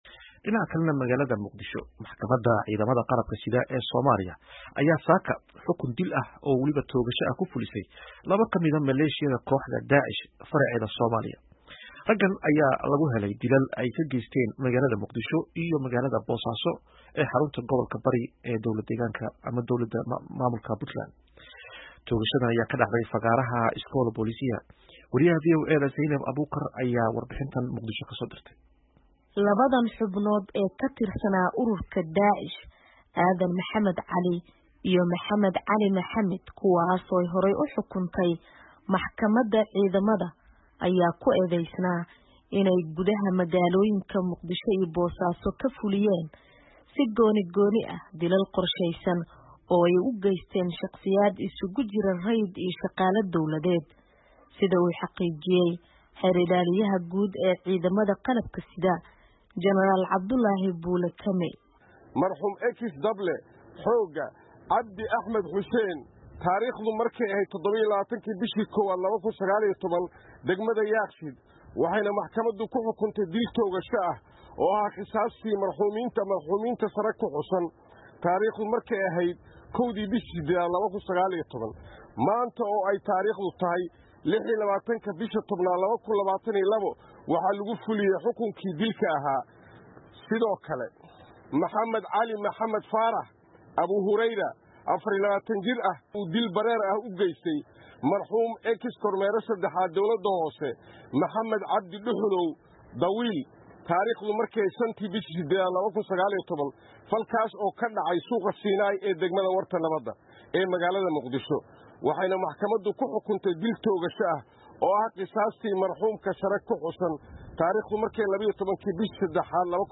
Warbixintan